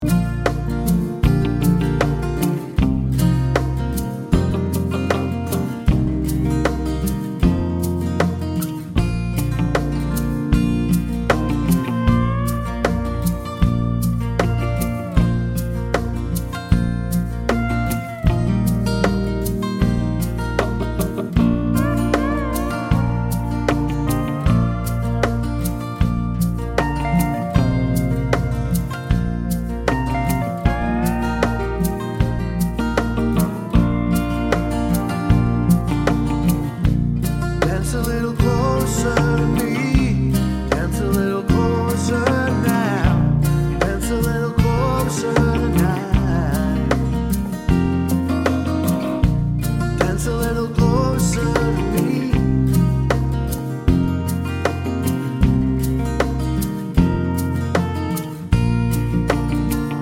no Backing Vocals Irish 4:36 Buy £1.50